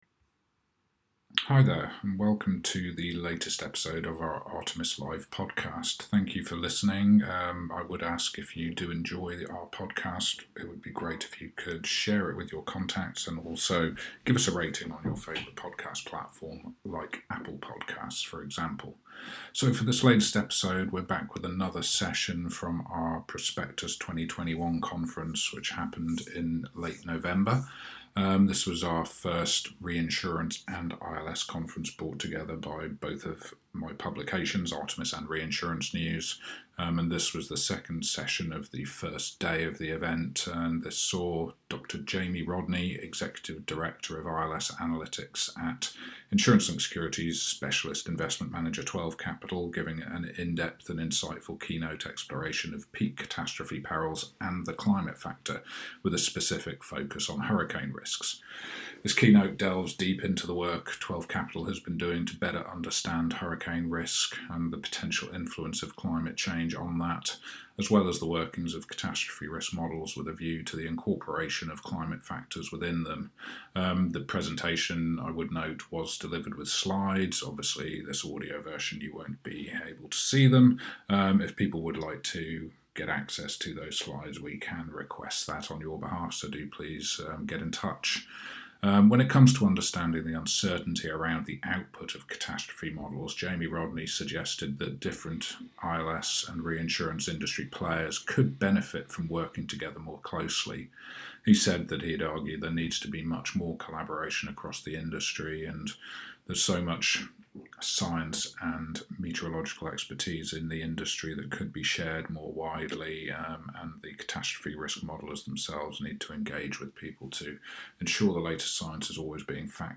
This was the second session of Prospectus 2021, a new annual reinsurance and insurance-linked securities (ILS) conference brought to you by Artemis in collaboration with sister title Reinsurance News in November 2020.